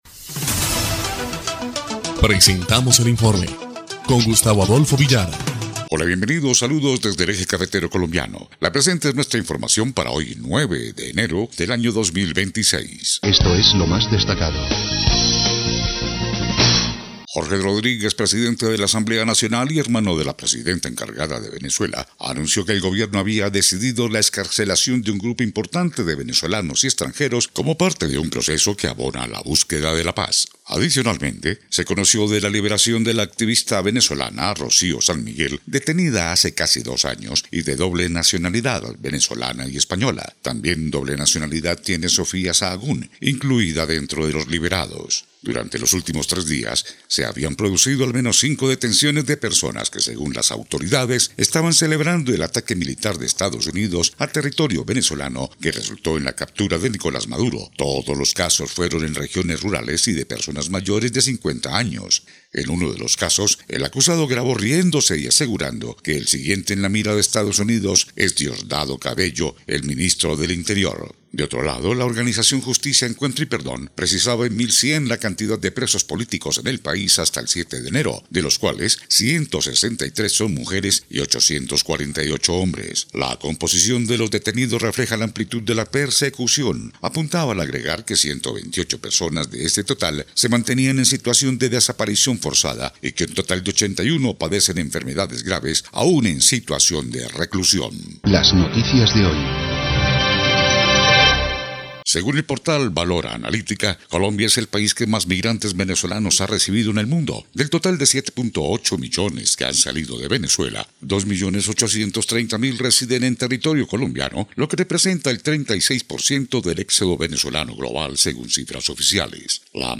EL INFORME 1° Clip de Noticias del 9 de enero de 2026